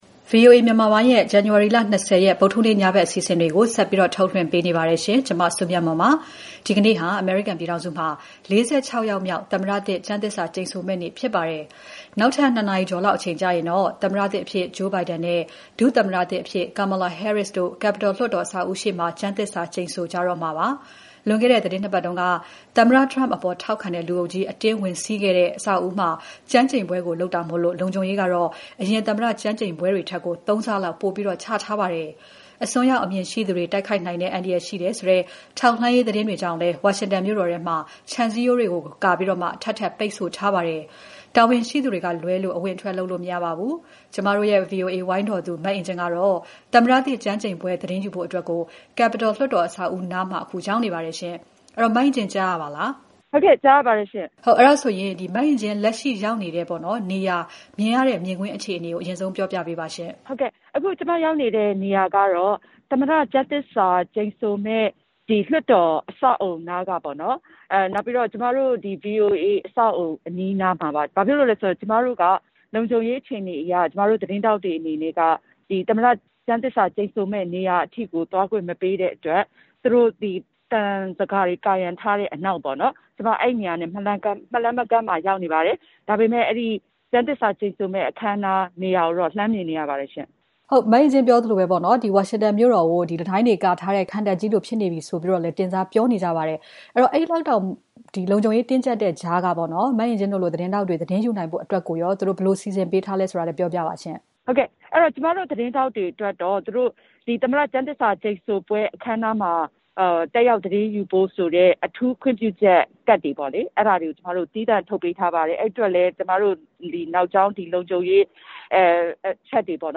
သမ္မတသစ်ကျမ်းသစ္စာဆိုပွဲမတိုင်ခင် ဗွီအိုအေသတင်းထောက်နဲ့ မေးမြန်းမှု